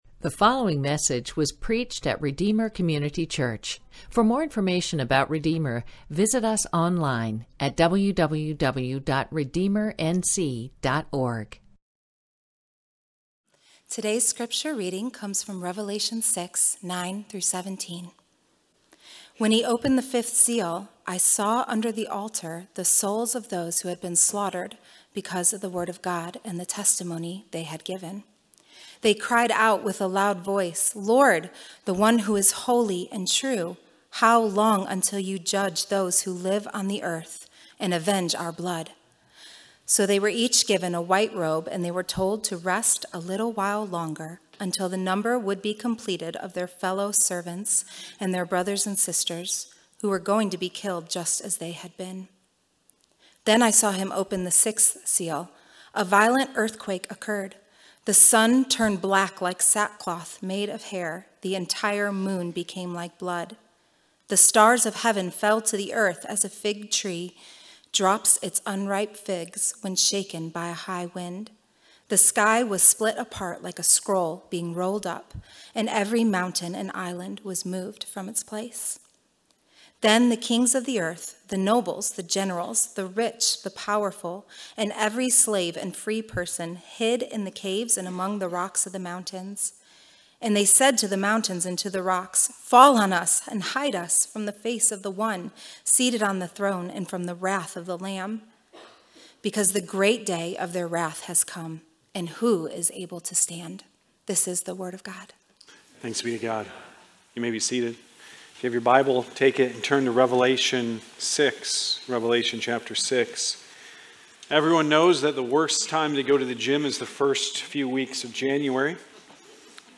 Sermons - Redeemer Community Church